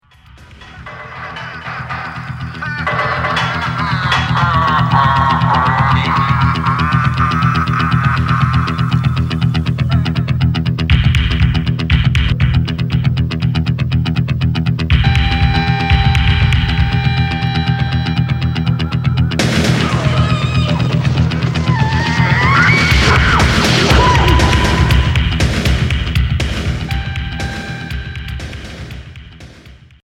Industriel expérimental